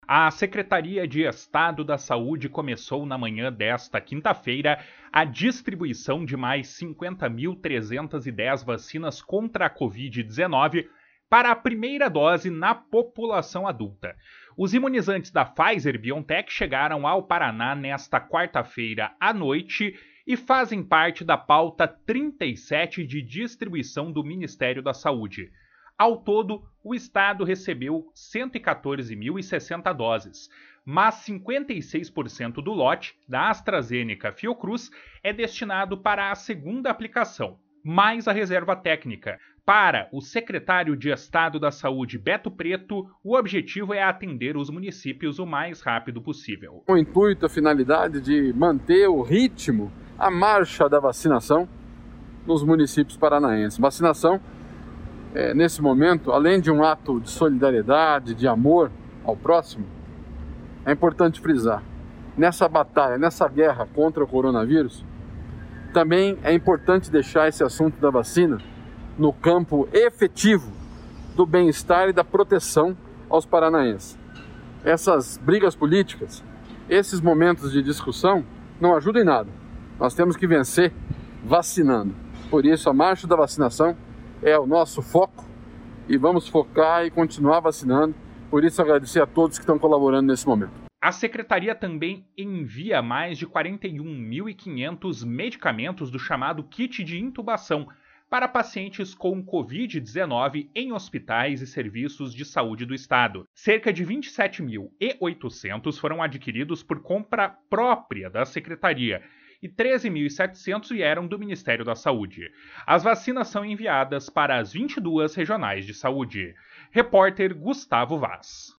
// SONORA BETO PRETO // A Secretaria também envia mais de 41.500 medicamentos do chamado “kit de intubação” para pacientes com Covid-19 em hospitais e serviços de saúde do Estado.